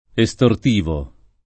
vai all'elenco alfabetico delle voci ingrandisci il carattere 100% rimpicciolisci il carattere stampa invia tramite posta elettronica codividi su Facebook estortivo [ e S tort & vo ] (meno bene estorsivo [ e S tor S& vo ]) agg.